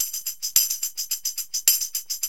TAMB LP 106.wav